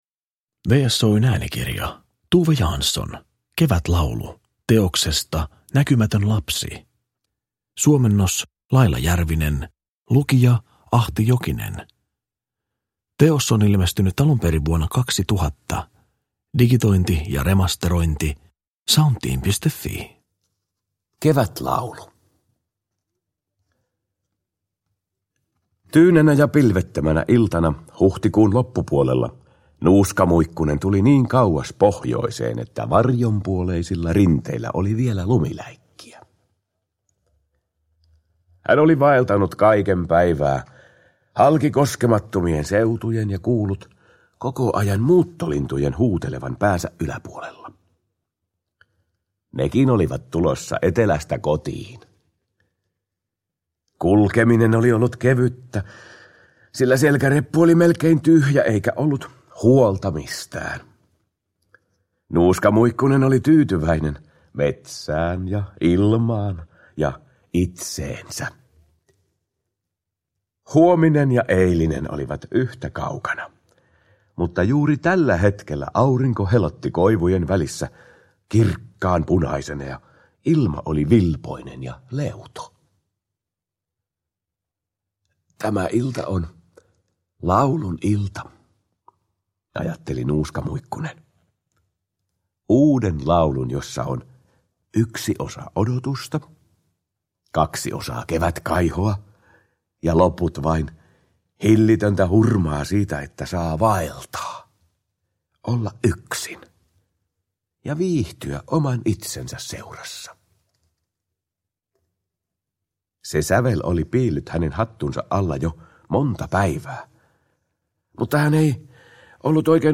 Kevätlaulu – Ljudbok